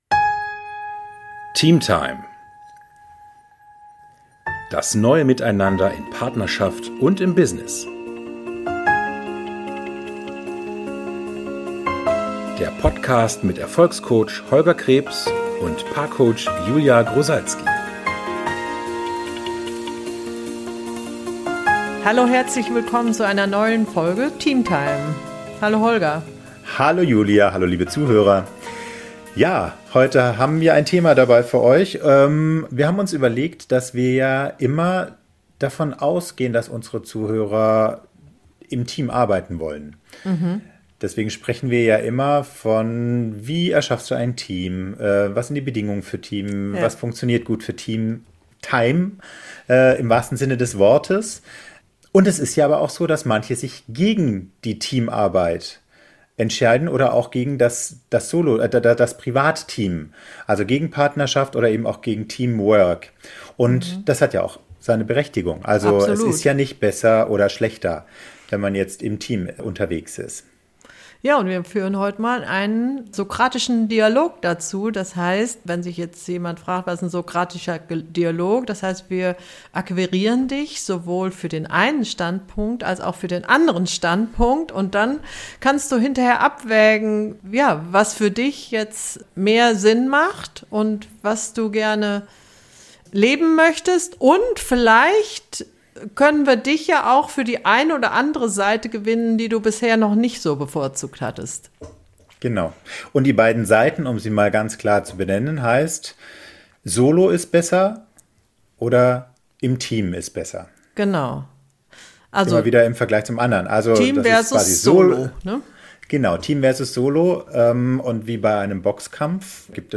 Ein sokratischer Dialog betrachtet zwei gegensätzliche Standpunkte oder Thesen eines Themas gleichberechtigt. Wir führen diesen Dialog heute zu den zwei Thesen: Solo ist besser und Team ist besser.